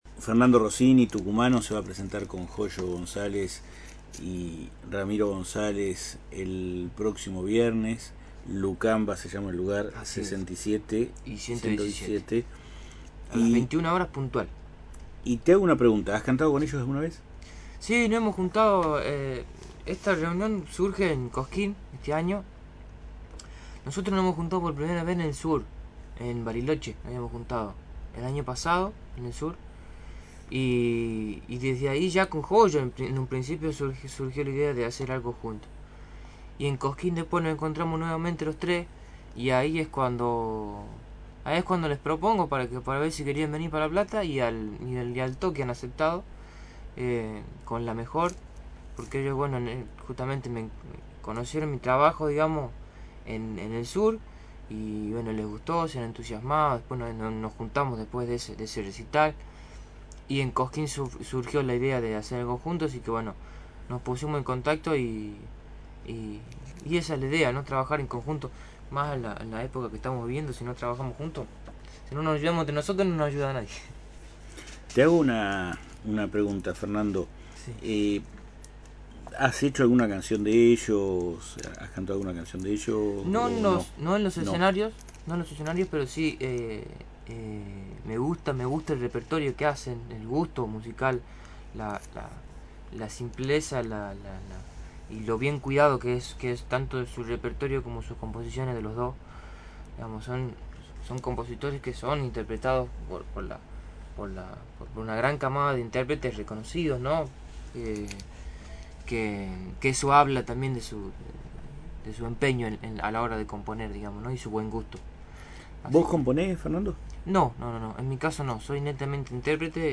Frecuencia: AM